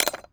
pgs/Assets/Audio/Doors/door_lock_fail_03.wav
door_lock_fail_03.wav